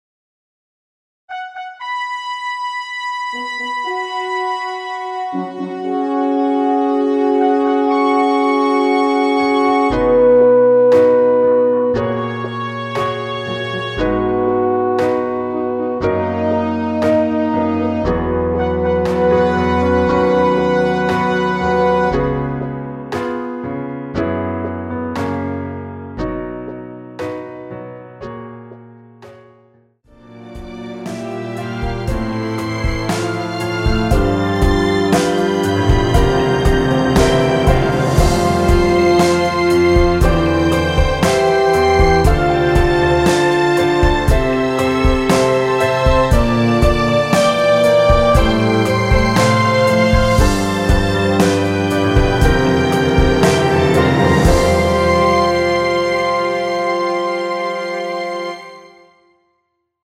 엔딩이 너무 길어 라이브에 사용하시기 좋게 짧게 편곡 하였습니다.(원키 미리듣기 참조)
원키에서(+6)올린 MR입니다.
앞부분30초, 뒷부분30초씩 편집해서 올려 드리고 있습니다.
중간에 음이 끈어지고 다시 나오는 이유는